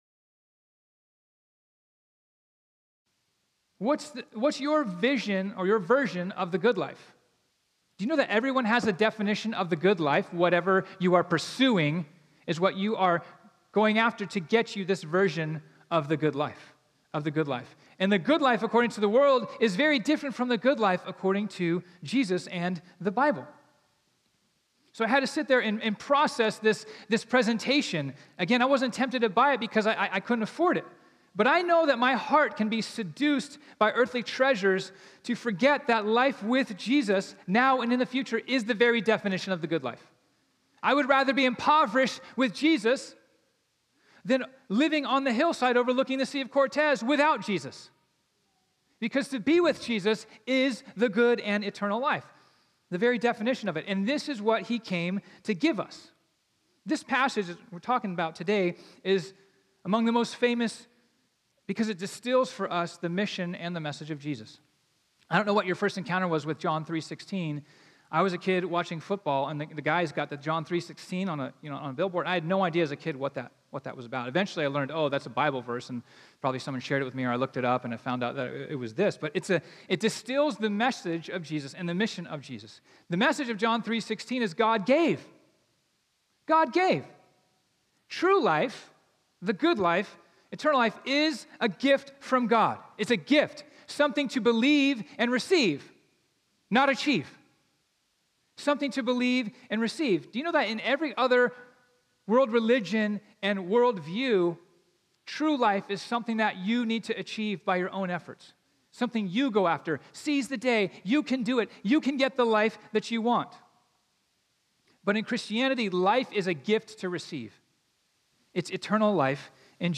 This sermon was originally preached on Sunday, October 13, 2019.